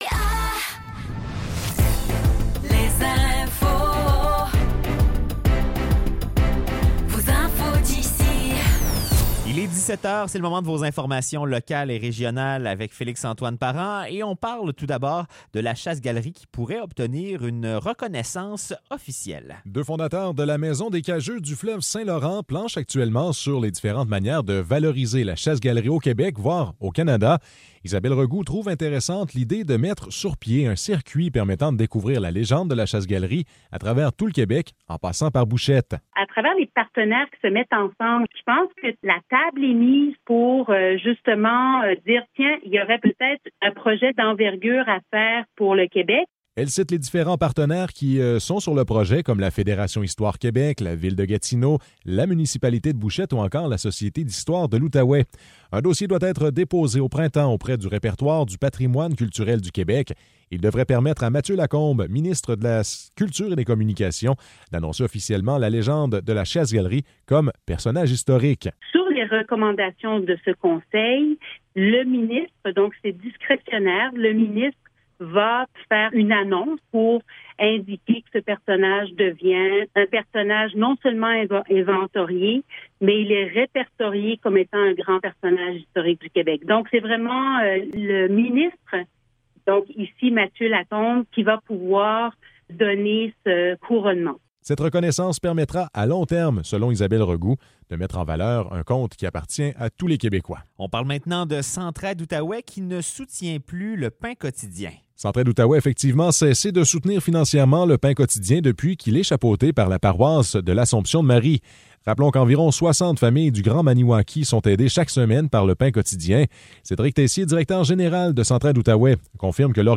Nouvelles locales - 6 février 2024 - 17 h